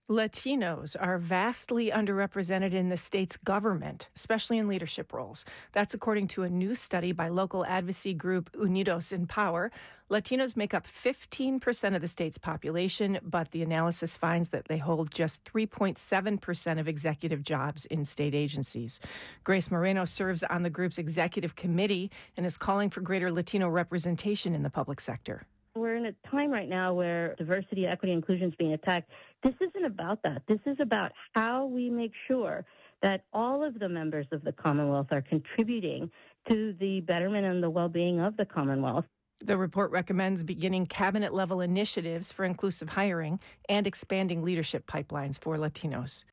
interv.m4a